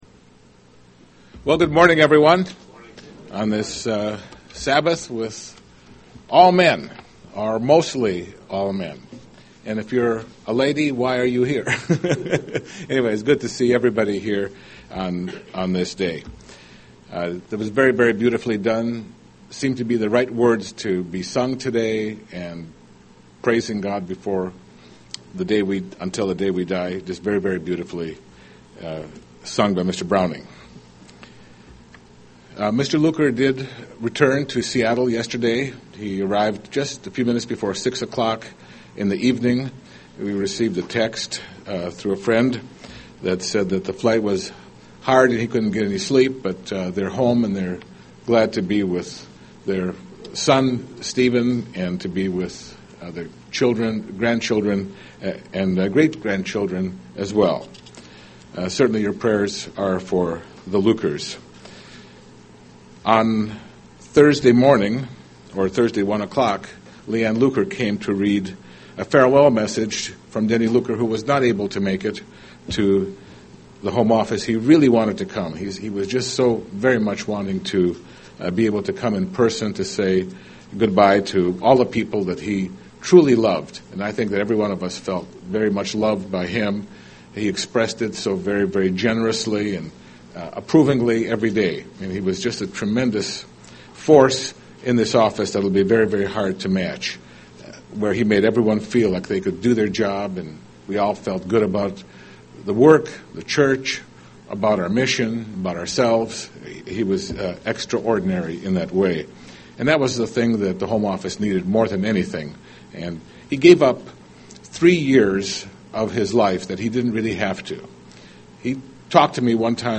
He who endures until the end will be saved (Matthew 24:13) UCG Sermon Transcript This transcript was generated by AI and may contain errors.